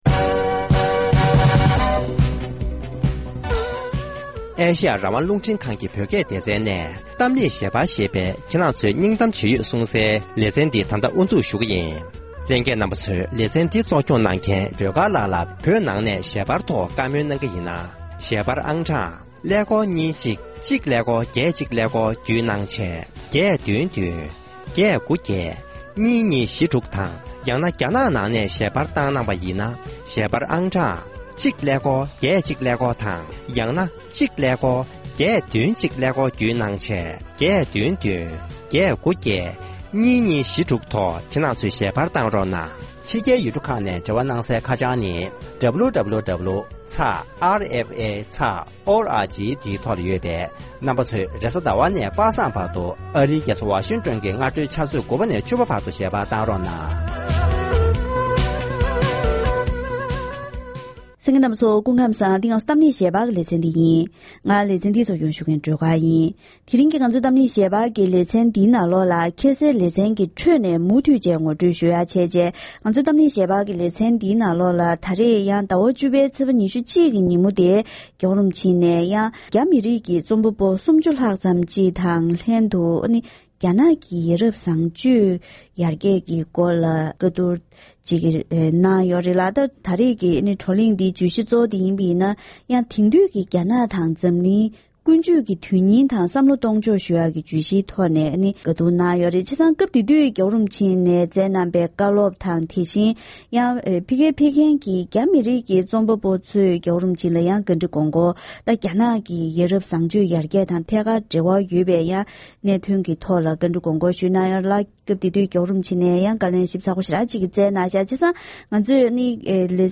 ༸གོང་ས་མཆོག་ཉེ་ཆར་རྒྱ་གར་ནང་ཆིབས་བསྒྱུར་སྐབས་བྷན་དྡ་ར་བོད་མིར་དམིགས་བསལ་བཀའ་སློབ་སྩལ་བ།